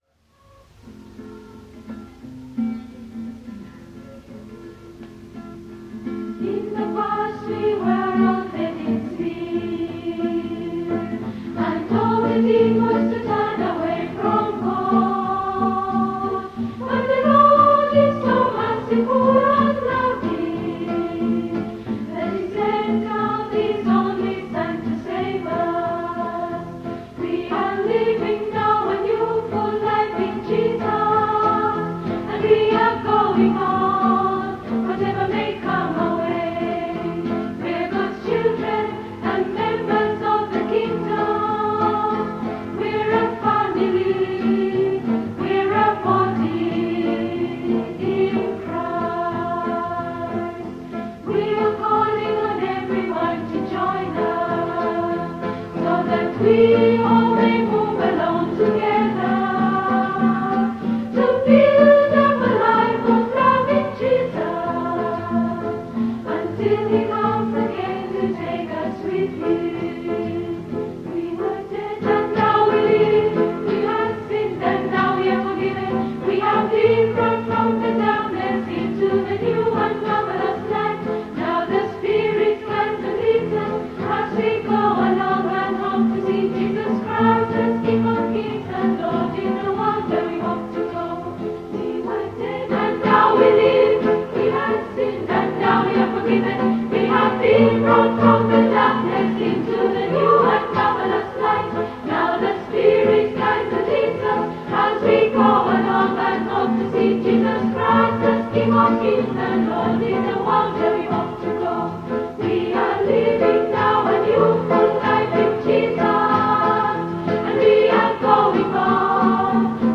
In their Hour of Praise they sang 27 items.